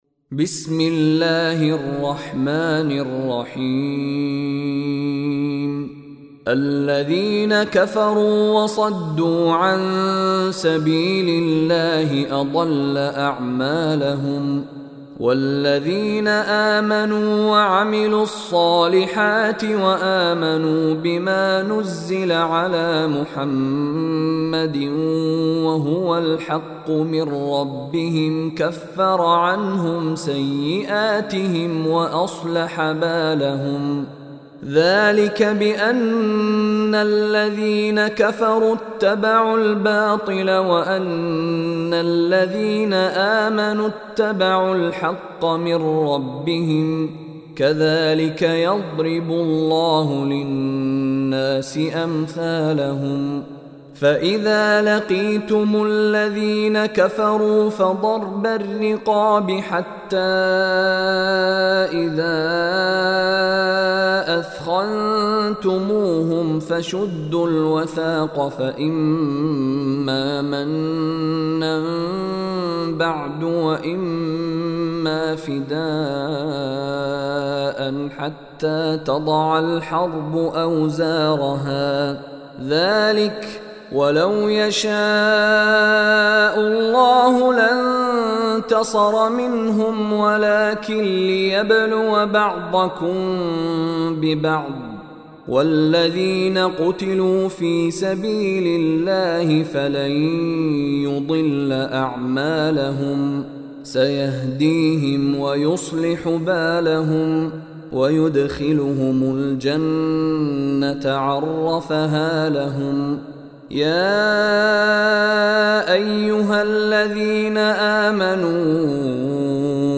Surah Muhammad Recitation by Mishary Rashid
Surah Muhammad is 47 chapter of Holy Quran. Listen online mp3, recitation in Arabic of Surah Muhammad in the voice of Sheikh Mishary Rashid Alafasy.